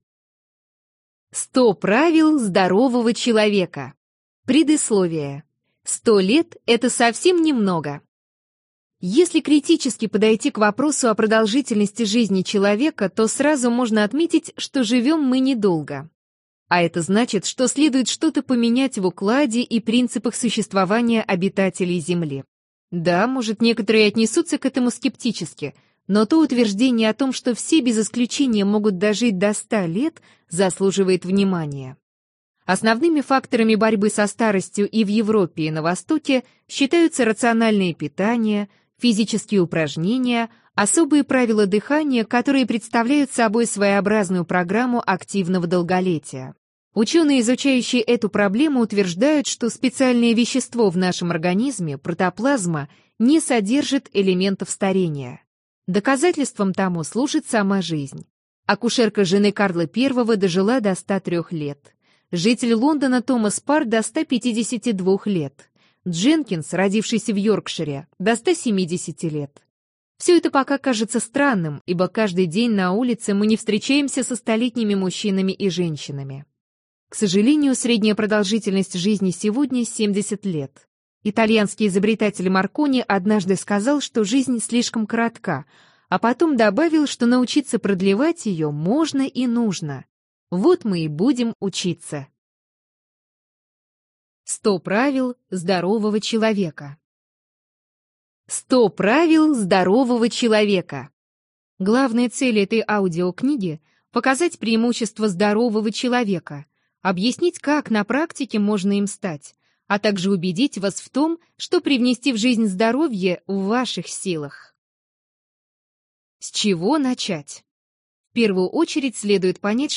Аудиокнига 100 простых правил здоровья | Библиотека аудиокниг